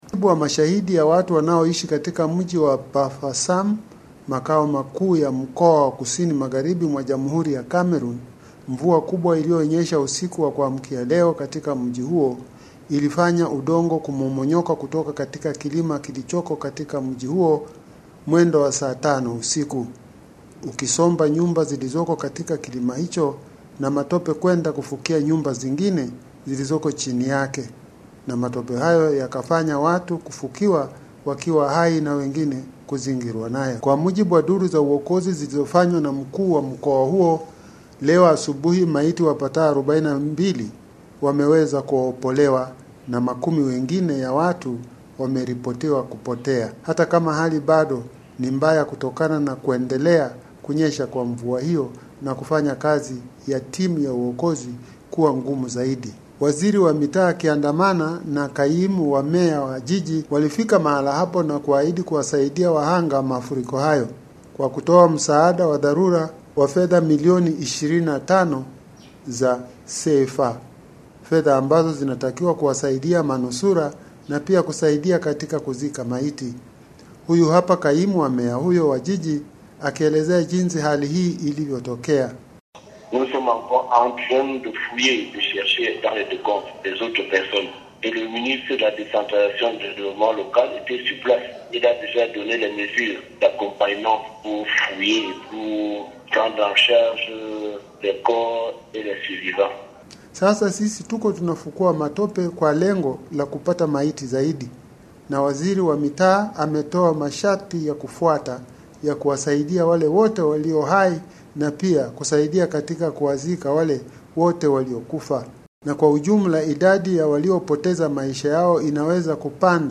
Kwa maelezo zaidi, tumtegee sikio mwandishi wetu wa Afrika ya Kati.